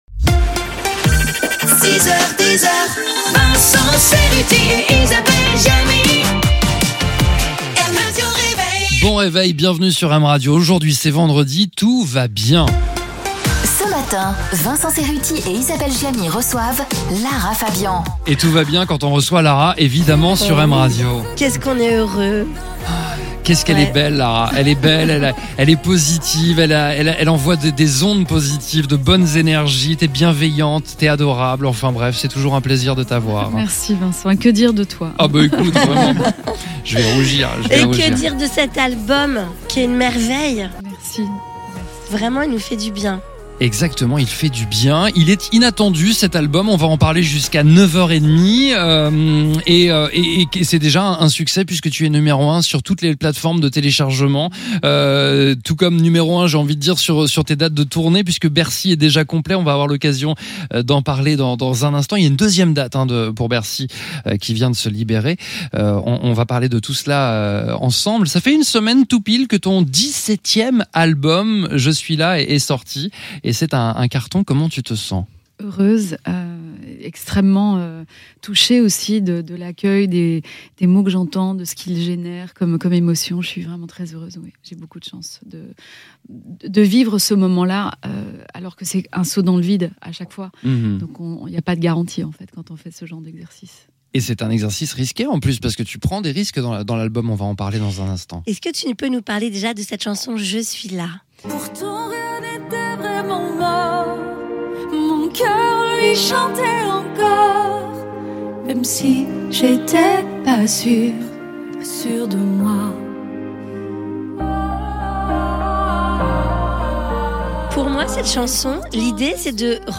podcast-mrr-itw-lara-fabian-wm-83517.mp3